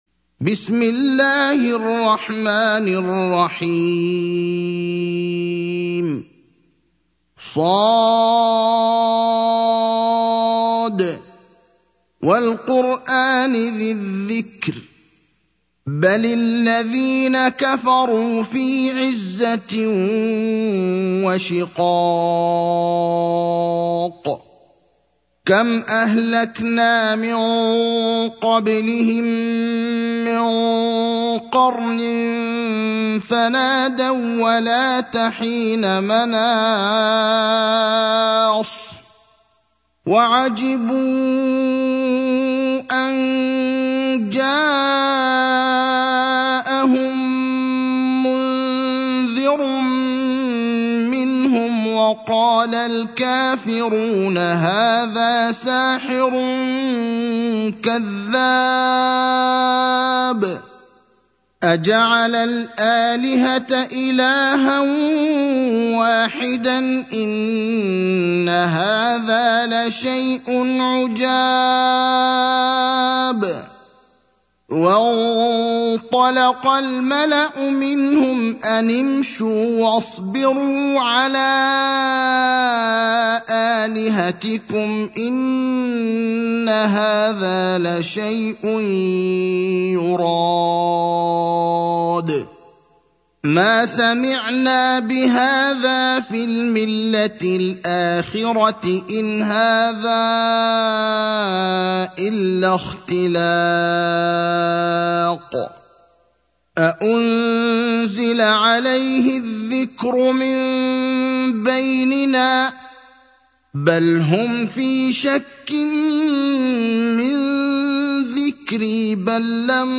المكان: المسجد النبوي الشيخ: فضيلة الشيخ إبراهيم الأخضر فضيلة الشيخ إبراهيم الأخضر ص (38)